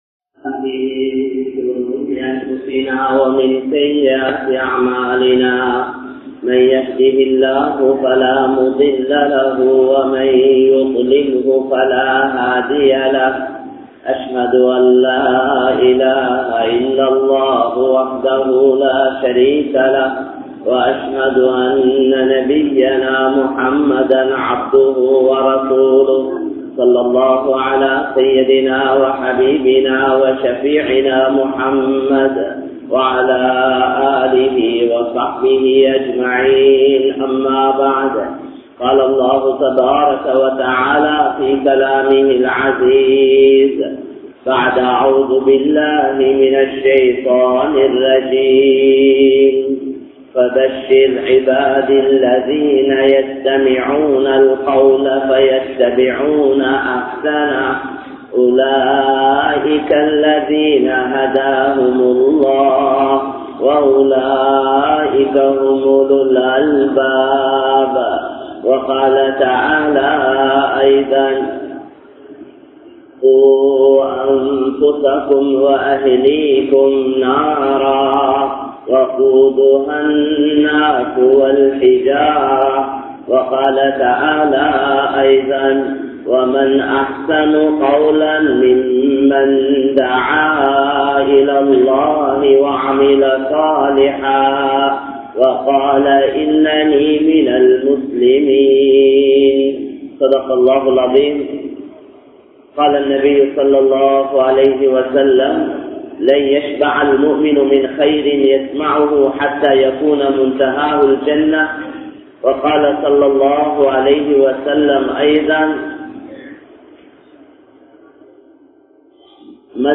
Padaiththavanin Sakthi (படைத்தவனின் சக்தி) | Audio Bayans | All Ceylon Muslim Youth Community | Addalaichenai
Colombo, GrandPass Markaz